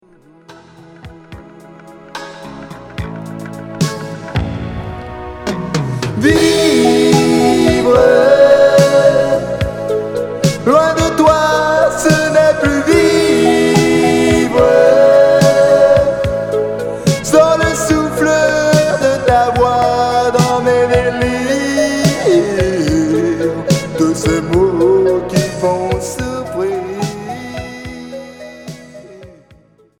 Slow rockabilly 50's Unique 45t retour à l'accueil